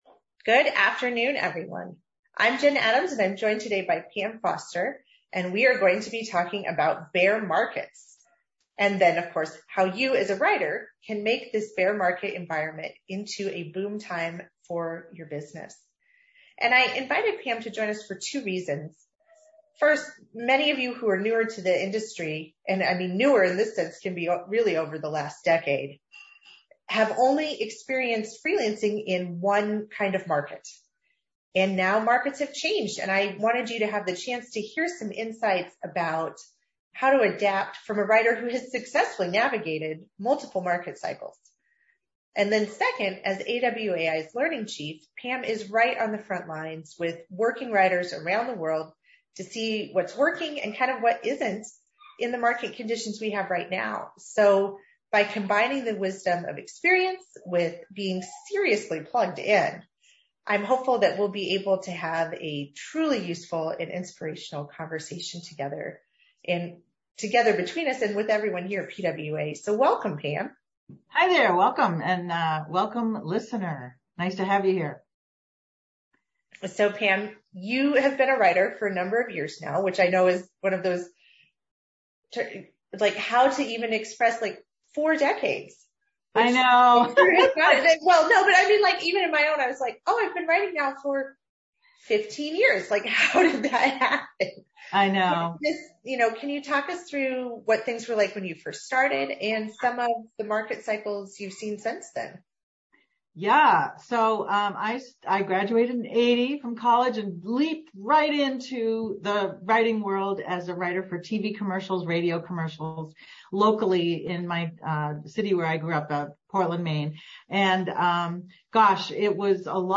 Event Playback Play Audio Interview